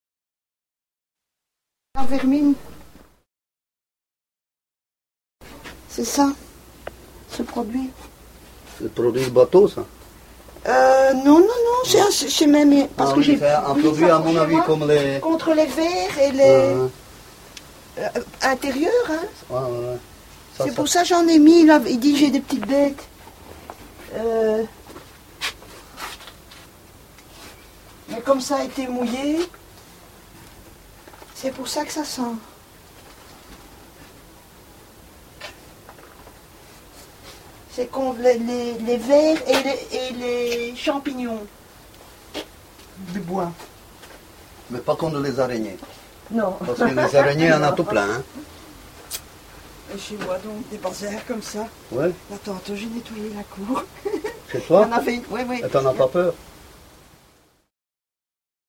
prononciation La Vermine